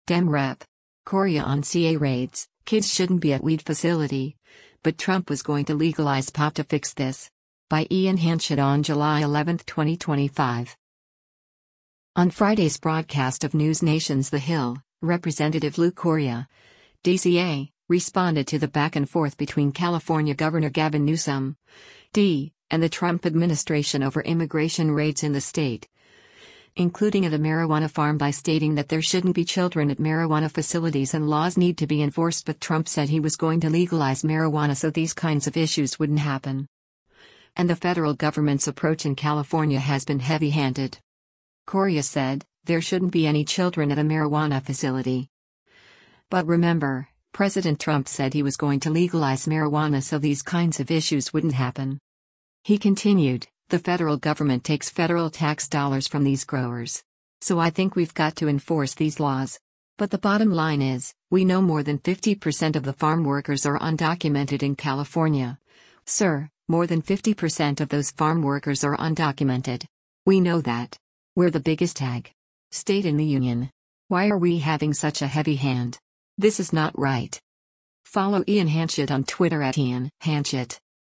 On Friday’s broadcast of NewsNation’s “The Hill,” Rep. Lou Correa (D-CA) responded to the back and forth between California Gov. Gavin Newsom (D) and the Trump administration over immigration raids in the state, including at a marijuana farm by stating that there shouldn’t be children at marijuana facilities and laws need to be enforced but “Trump said he was going to legalize marijuana so these kinds of issues wouldn’t happen.” And the federal government’s approach in California has been heavy-handed.